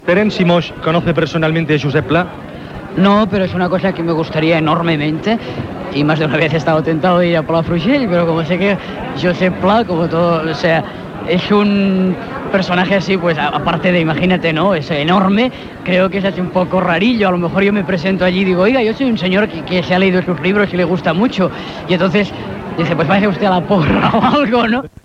Entrevista a l'escriptor Terenci Moix, guanyador del Premi Josep Pla.
Extret de Crònica Sentimental de Ràdio Barcelona emesa el dia 29 d'octubre de 1994.